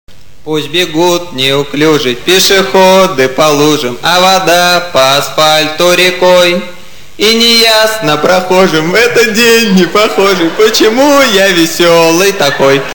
P/S/ Песню поет сам :)